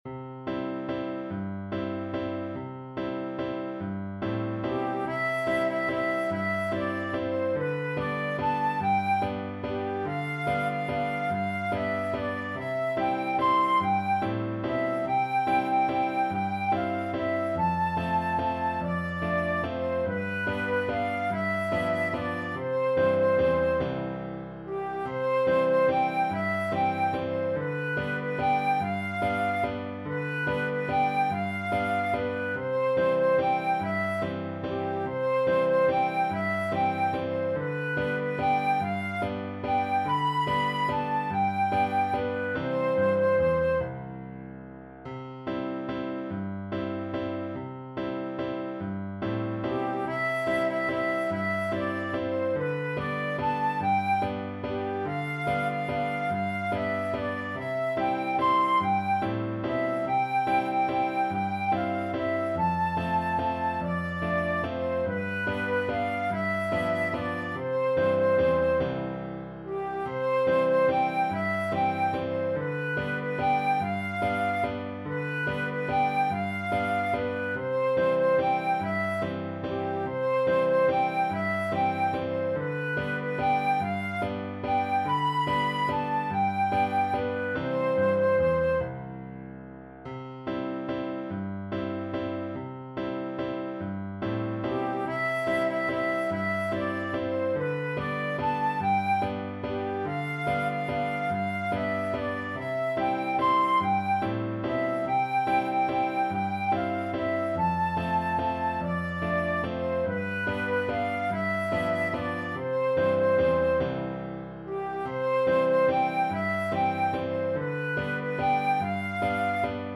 Flute version
Steady one in a bar .=c.48
3/8 (View more 3/8 Music)
Swiss